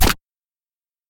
Download Free Boxing Fight Sound Effects
Boxing Fight